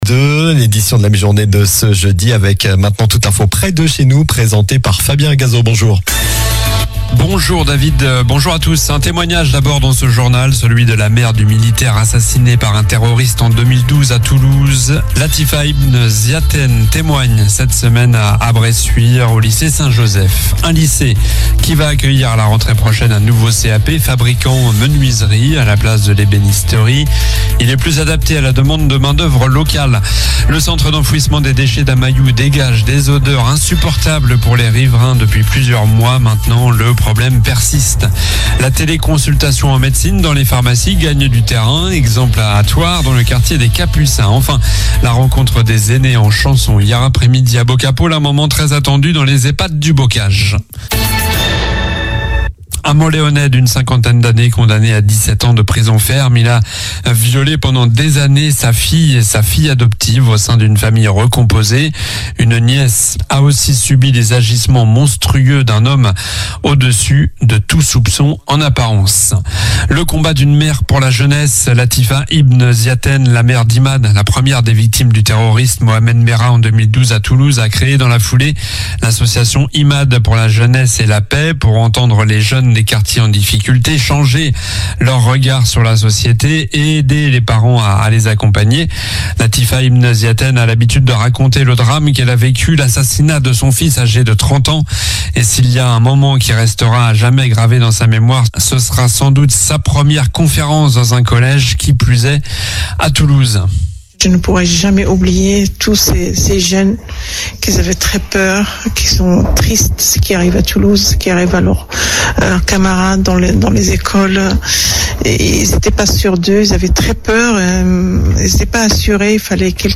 Journal du Jeudi 16 mai (midi)